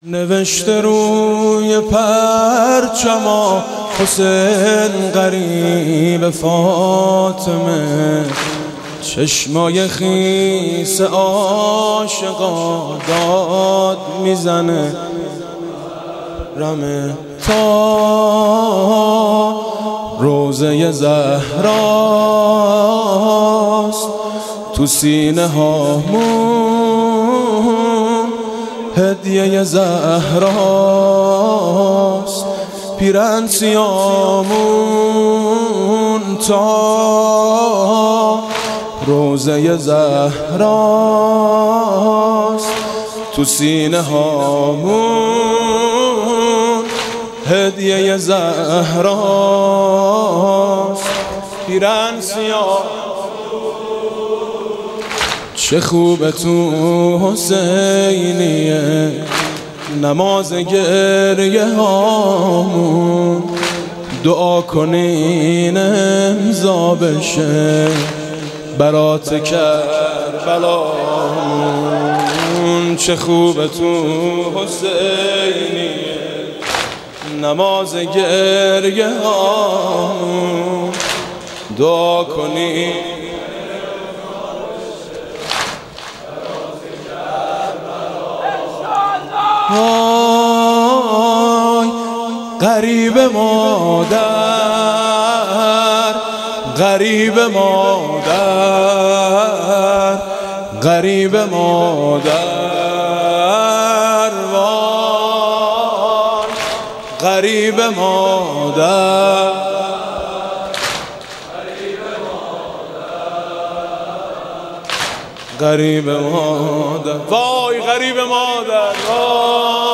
خواننده کشورمان امسال نیز طبق سنوات گذشته به زادگاهش بندرعباس رفت و در هیاتی در محله کودکی اش برای امام حسین و اهل بیتش مداحی کرد.
برچسب ها: مداحی محرم رضا صادقی صراط